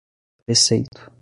Uitgesproken als (IPA)
/pɾeˈsej.tu/